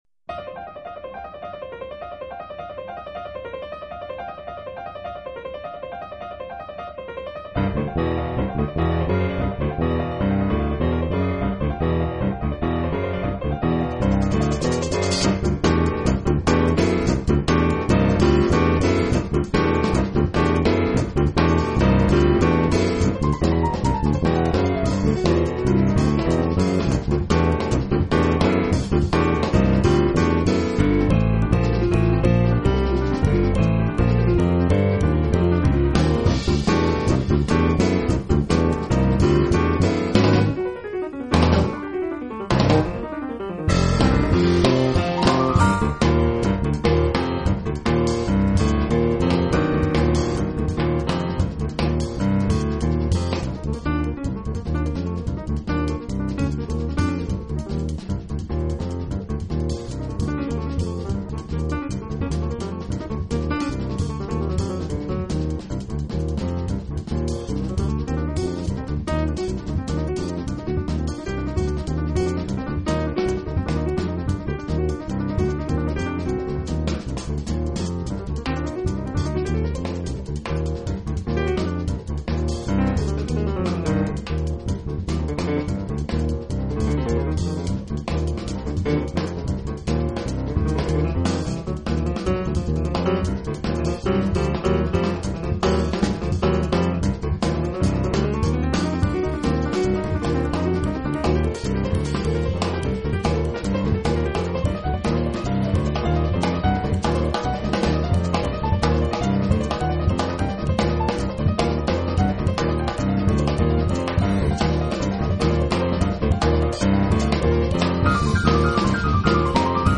音樂類別 ： 爵士樂 ． 鋼琴
專輯特色 ： 從原典的即興到電子的節奏，觸動心弦的新爵士概念